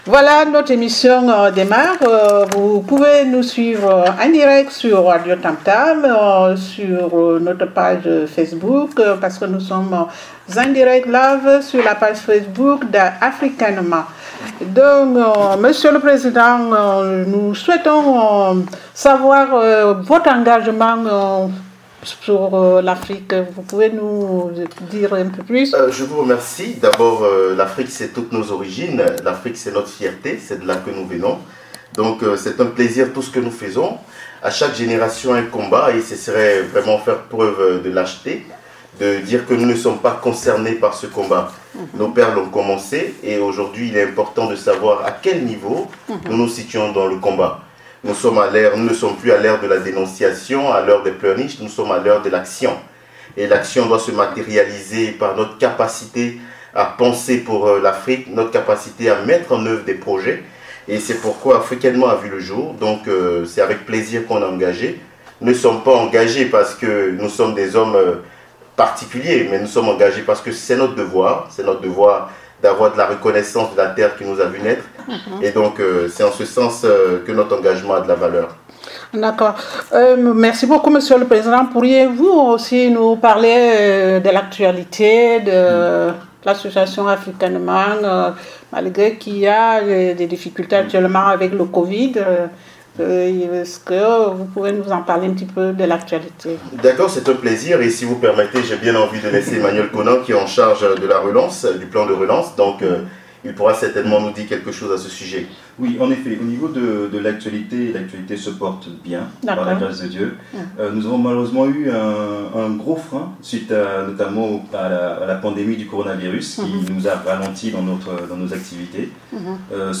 Suivez nous en direct sur l’application radio tam tam et sur la page Facebook Afrikainement. 1h d’échange sur la thématique de bancarisation qui reste encore très faible en Afrique subsaharienne. Les causes ; les enjeux et perspectives seront au cœur de cette émission.